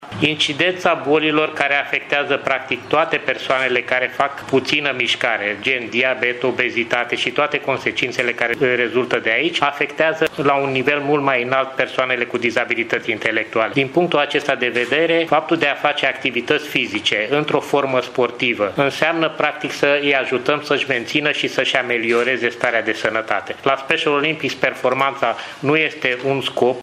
Programul manifestării a fost anunțat, astăzi, în cadrul unei conferințe de presă.